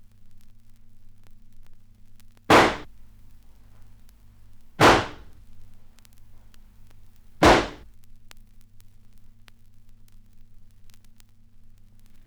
• horror hits and vinyl crackle.wav
horror_hits_and_vinyl_crackle_Fgy.wav